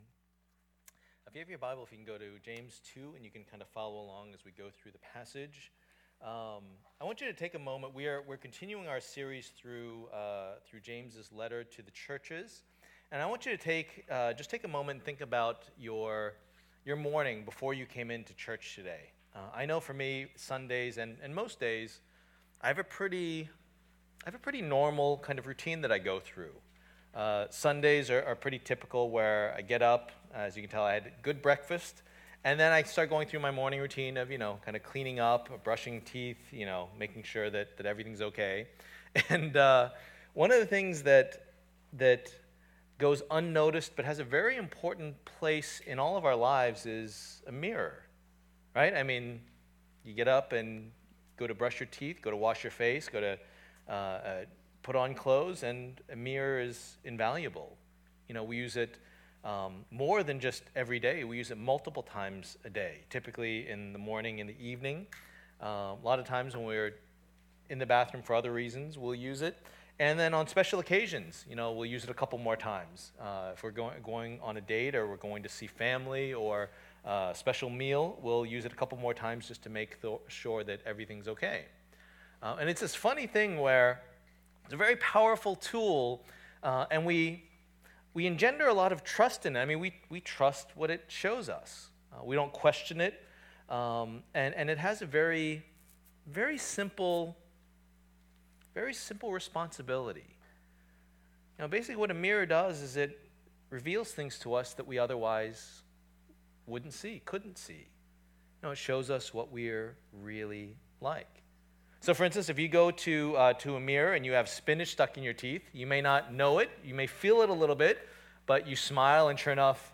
Passage: James 2:1-13 Service Type: Lord's Day